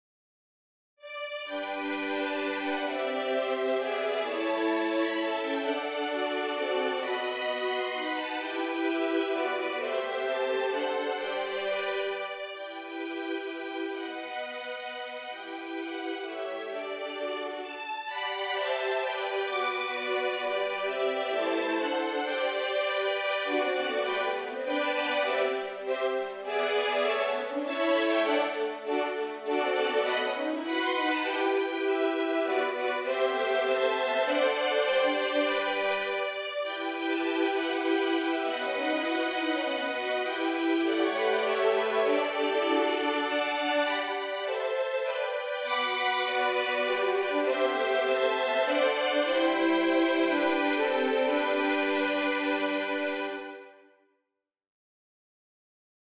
Contemporary and Classical Arr.